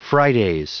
Prononciation du mot fridays en anglais (fichier audio)
Prononciation du mot : fridays